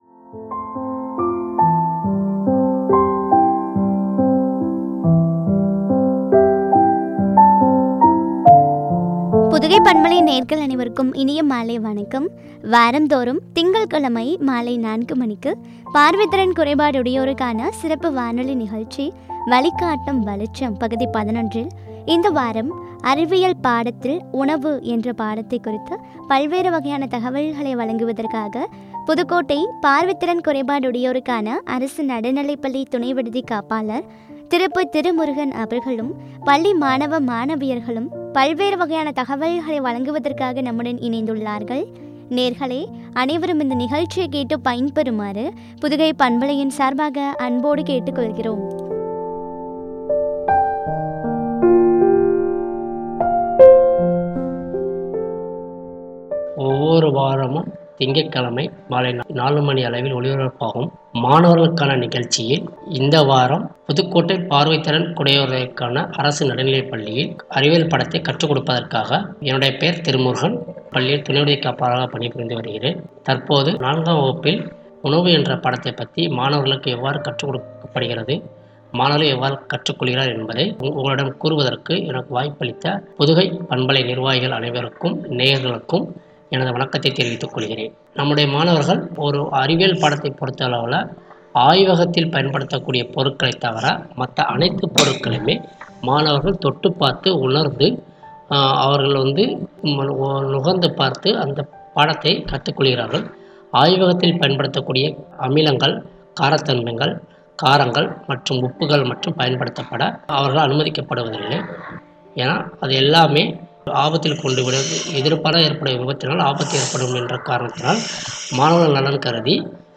“அறிவியல் பாடம் உணவு” குறித்து வழங்கிய உரையாடல்.